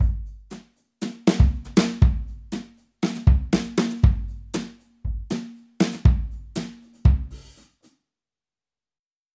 BFD 3.5 是 FXpansion 旗舰级原声鼓虚拟乐器，主打超真实多麦采样 + 深度物理建模 + 专业级混音控制，是影视、摇滚、金属、爵士等风格的顶级鼓制作工具BFD Drums。